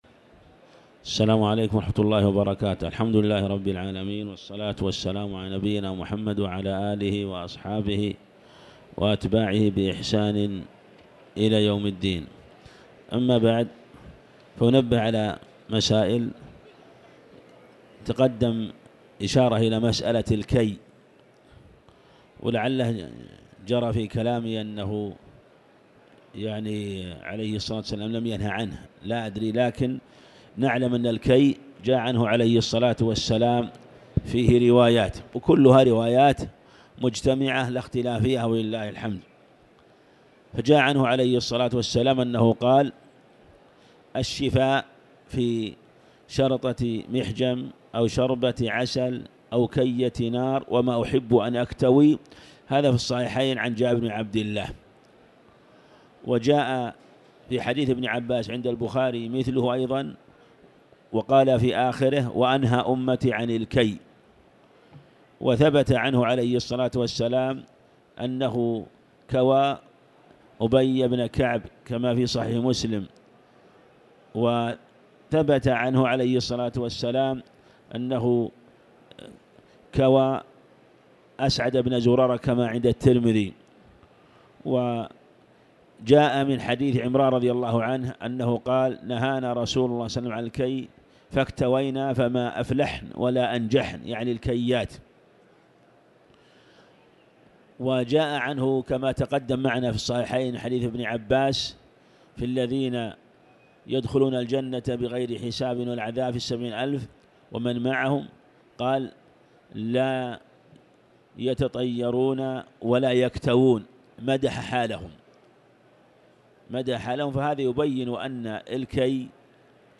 تاريخ النشر ٩ رمضان ١٤٤٠ هـ المكان: المسجد الحرام الشيخ